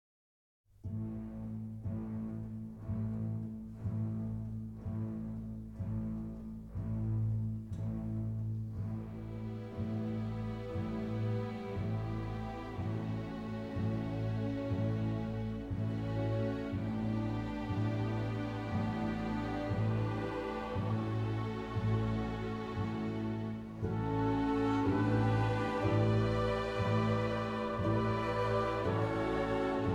Concert Music